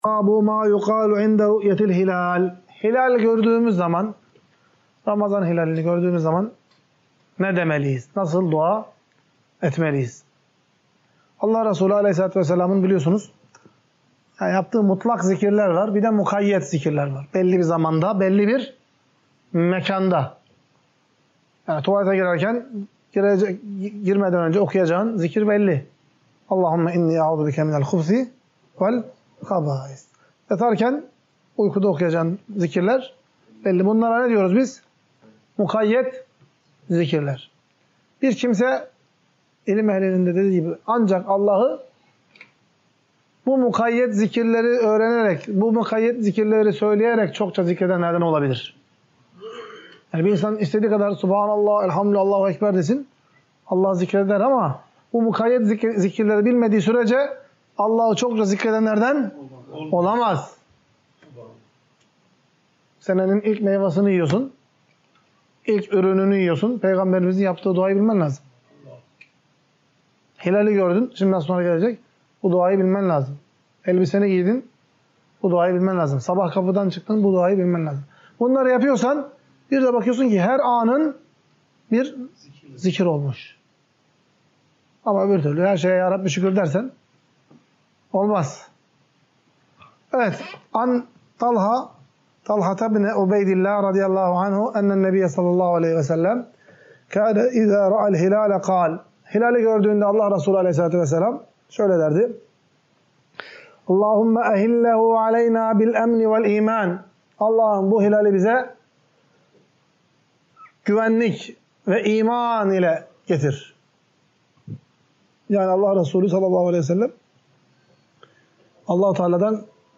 Ders - 41. BÖLÜM | HİLAL GÖRÜLDÜĞÜNDE YAPILACAK DUA - Taybe İlim